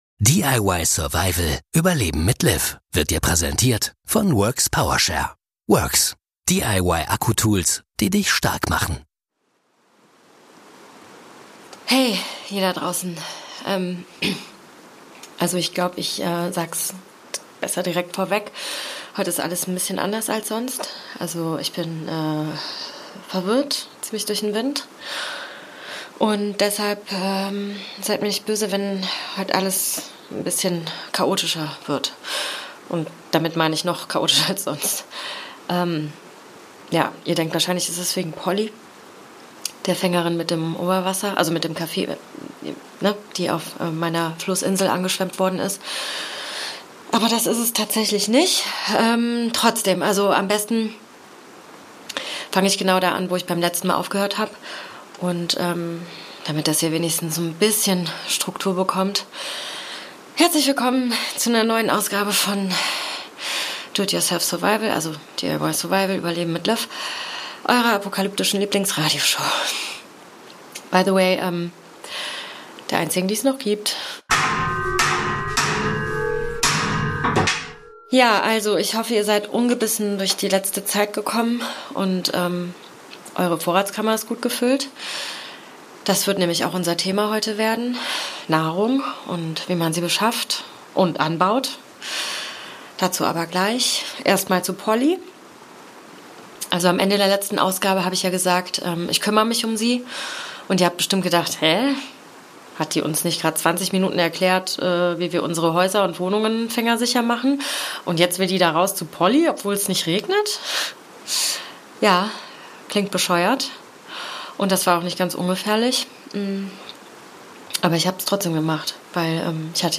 Zum Glück hat sie noch genug übrig, um uns zu erklären, wie man in der Zombie-Apokalypse Essen besorgt und anbaut. Wie man sich mit einfachen Mitteln ein Hochbeet zimmert, was alles auf eine apokalyptische Einkaufsliste gehört und wie man Vorräte vor Schädlingen schützt. DIY Survival ist ein Fiction-Podcast